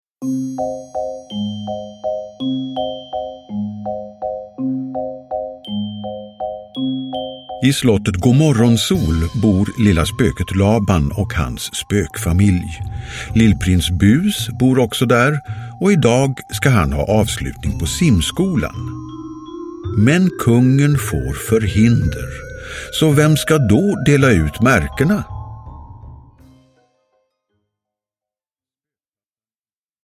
Spökpappan i simskolan – Ljudbok – Laddas ner